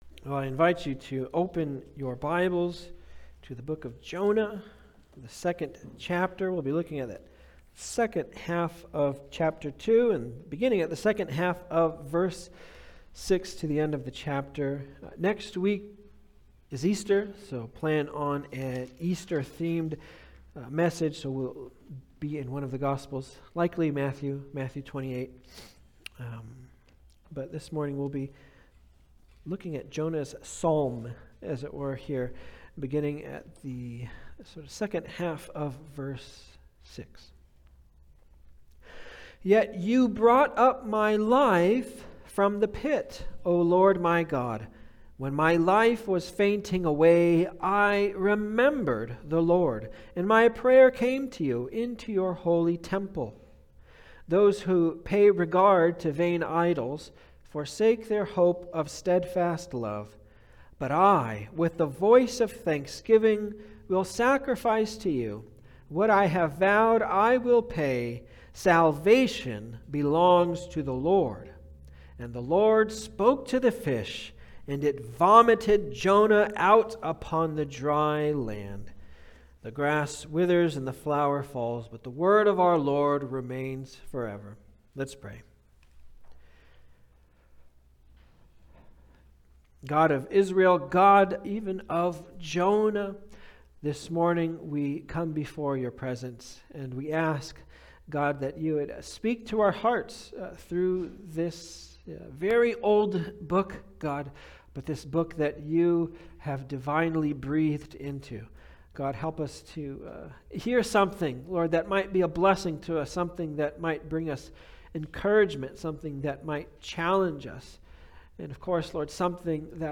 Jonah 2025 Passage: Jonah 2:6b-10 Service Type: Sunday Service « Jonah’s Descent He Is Risen!